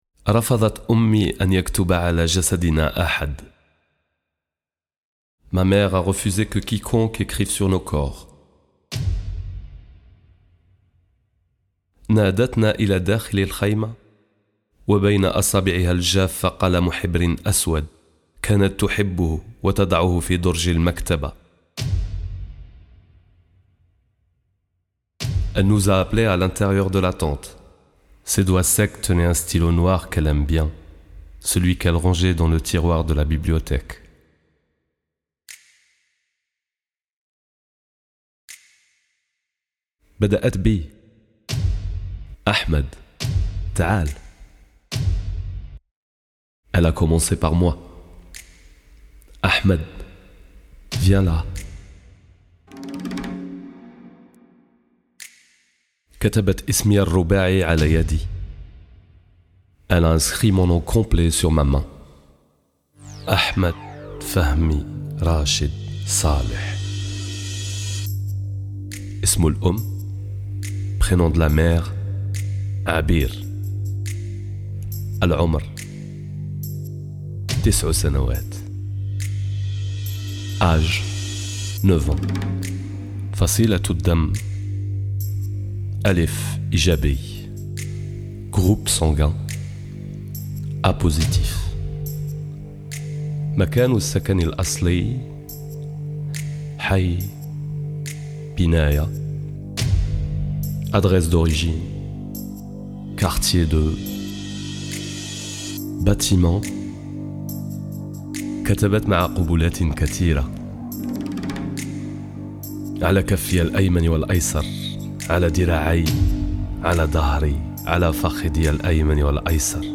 Lecture en français et en arabe, création musicale, enregistrement et mixage